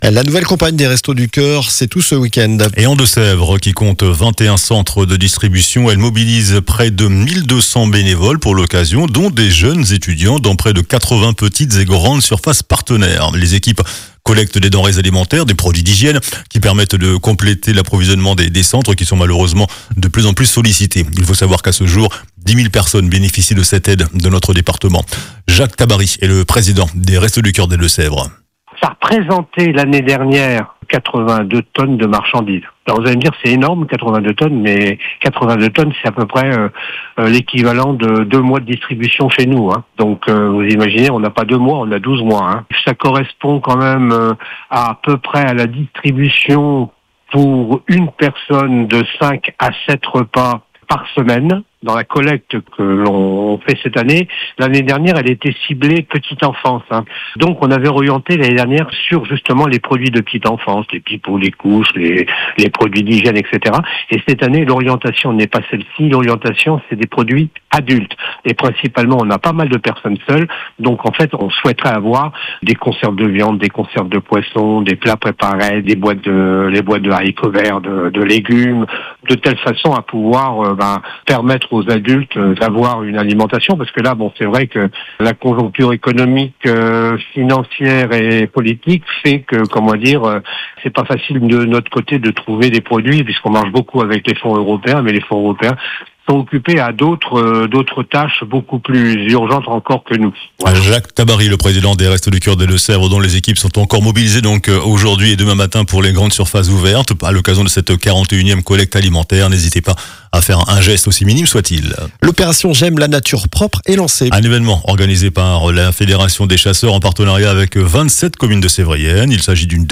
JOURNAL DU SAMEDI 07 MARS